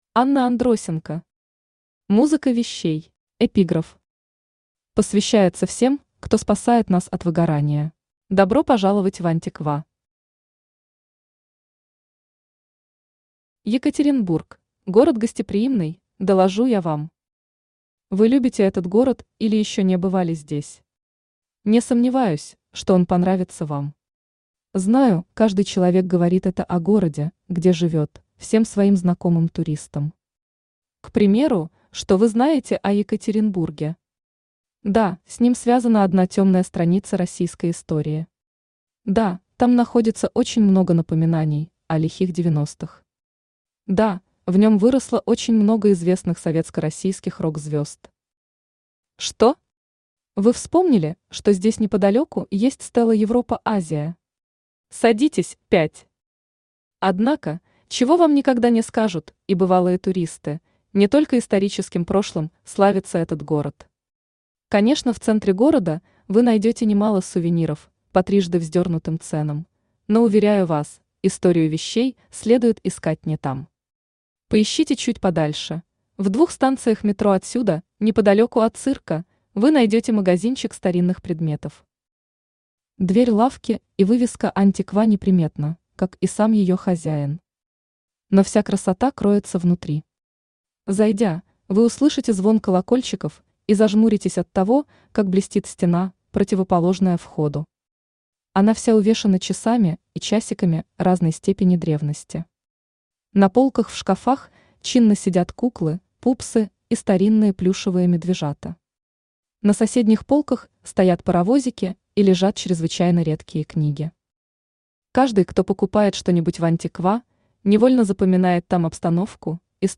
Aудиокнига Музыка вещей Автор Анна Андросенко Читает аудиокнигу Авточтец ЛитРес.